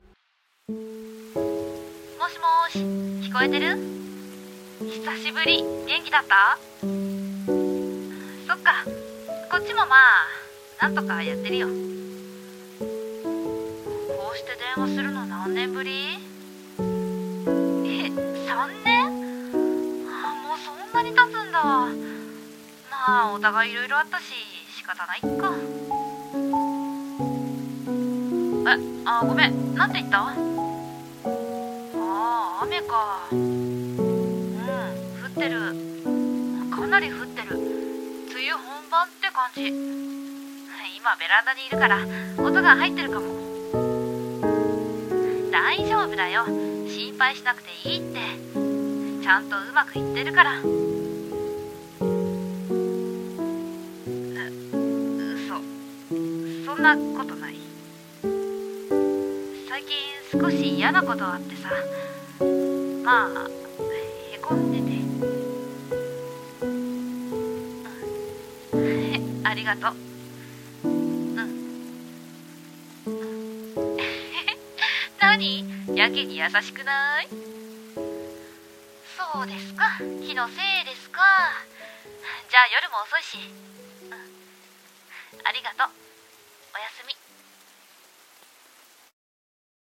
【1人声劇】こんばんは。聞こえてる？